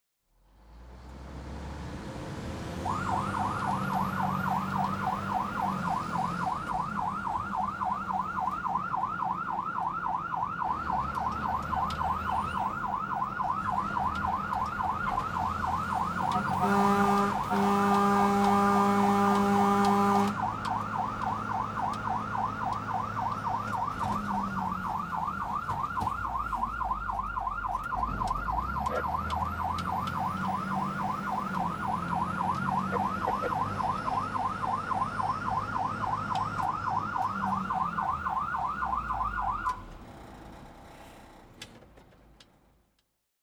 transport
Fire Truck Horn Inside